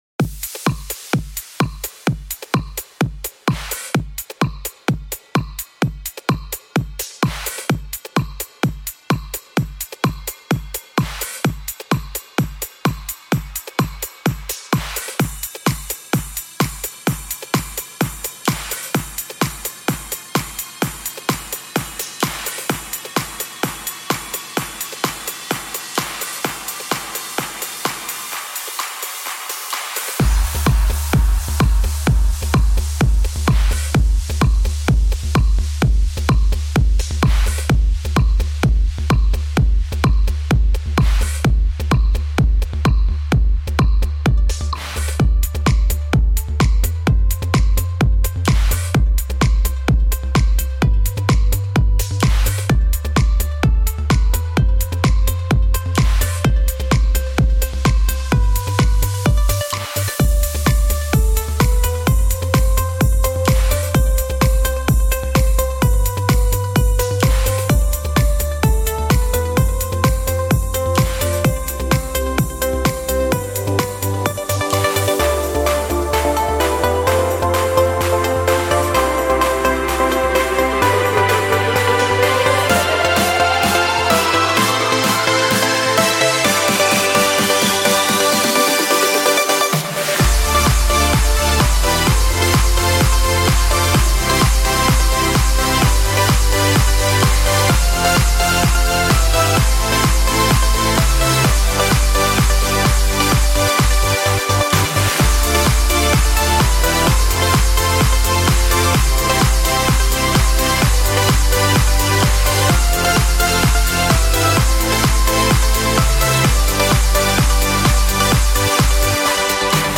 genre:house